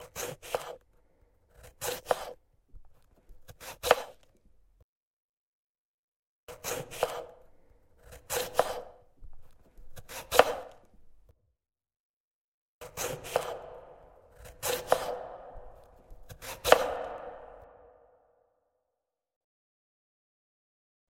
На этой странице собраны натуральные аудиоэффекты, связанные с огурцами: от хруста свежего овоща до звуков его выращивания.
Звук разрезания огурца ножом